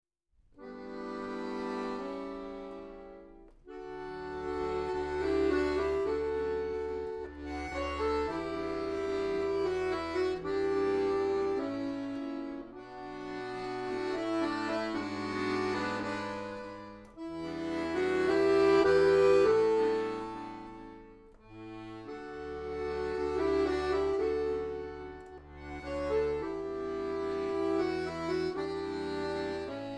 Accordion soloist